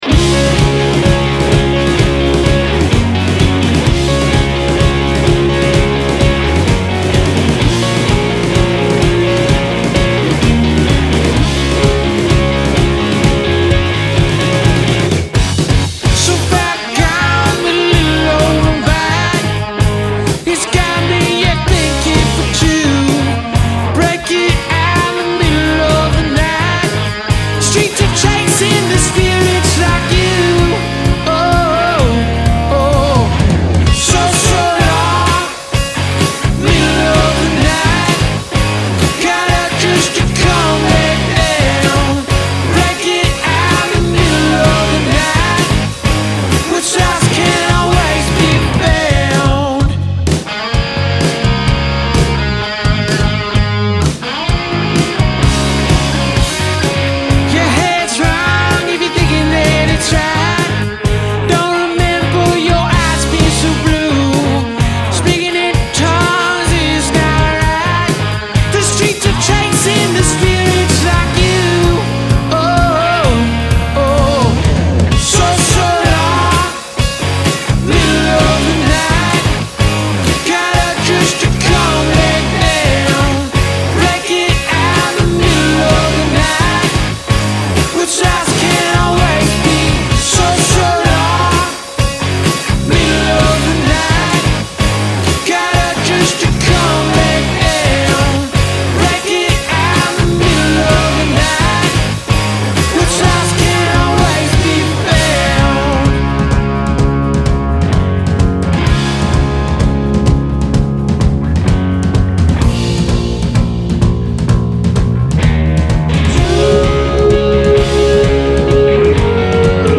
pop/rock